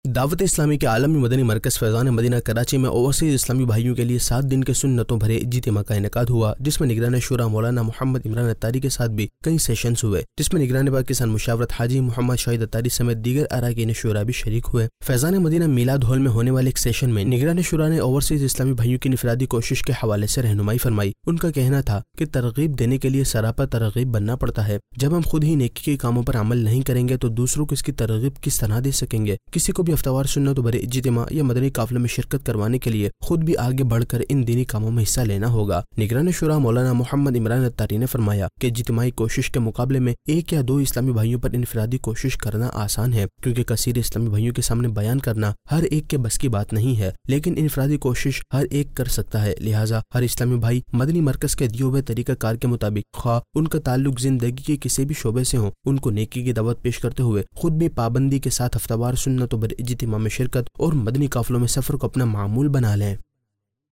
News Clips Urdu - 08 November 2023 - Aalimi Madani Markaz Mein Overseas Islami Bhaiyon Kay Liye Ijtima Munaqid Hua Nov 13, 2023 MP3 MP4 MP3 Share نیوز کلپس اردو - 08 نومبر 2023 - عالمی مدنی مرکز میں اوورسیز اسلامی بھائیوں کے لئے اجتماع منعقد ہوا